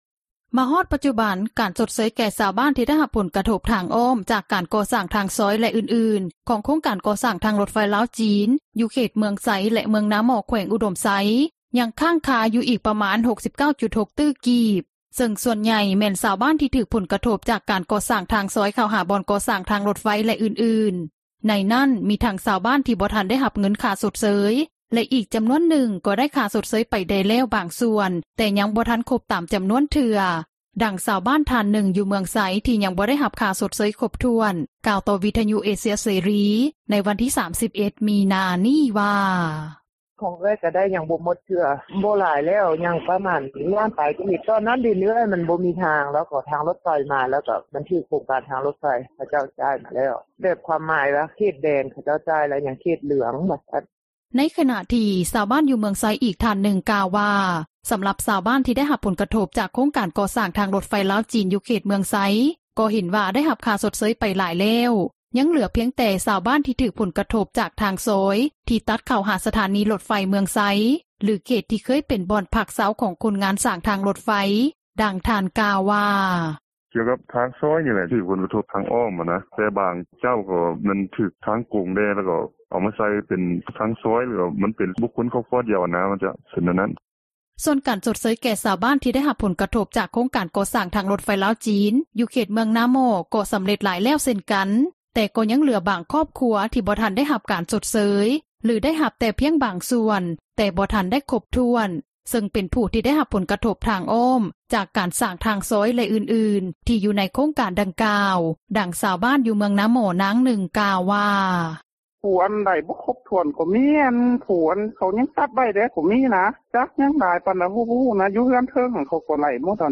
ດັ່ງຊາວບ້ານ ທ່ານນຶ່ງ ຢູ່ເມືອງໄຊ ທີ່ຍັງບໍ່ໄດ້ຮັບຄ່າຊົດເຊີຽຄົບຖ້ວນ ກ່າວຕໍ່ວິທຍຸເອເຊັຽເສຣີ ໃນວັນທີ່ 31 ມິນາ ນີ້ວ່າ:
ດັ່ງຊາວບ້ານ ຢູ່ເມືອງນາໝໍ້ ນາງນຶ່ງ ກ່າວວ່າ: